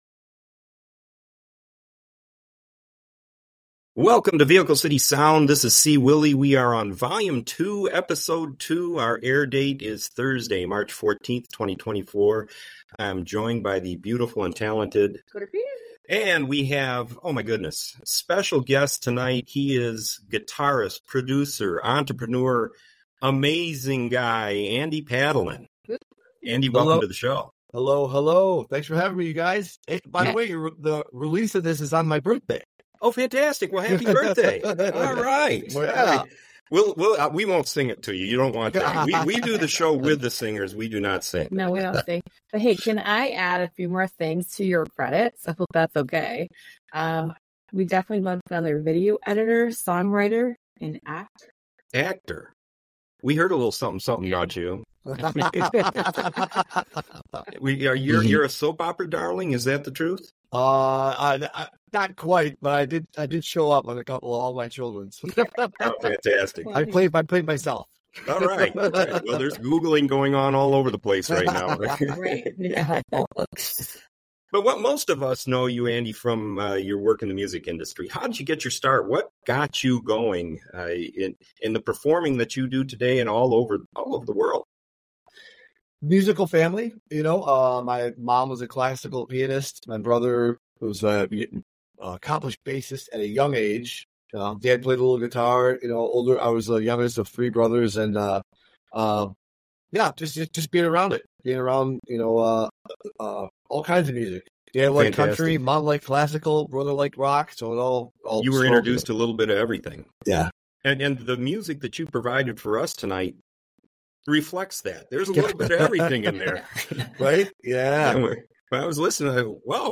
Fun interview and even better tunes!